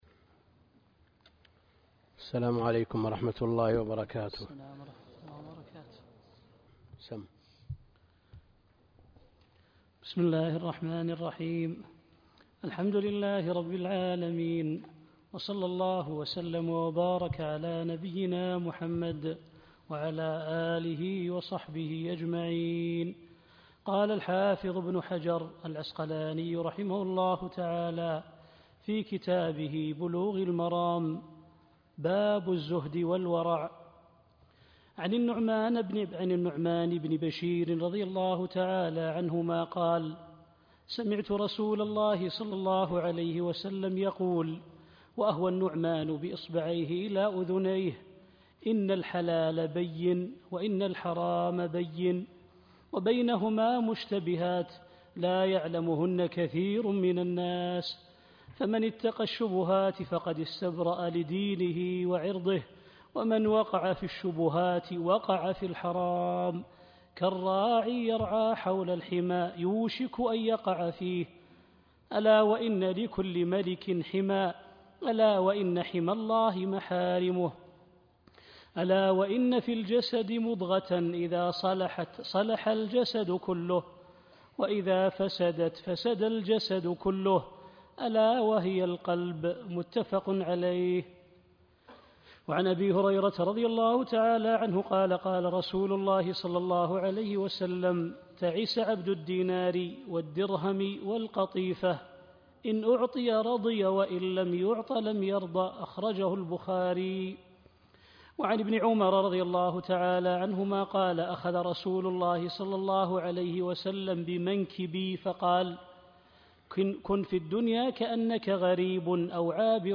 الدرس (4) كتاب الجامع من بلوغ المرام - الدكتور عبد الكريم الخضير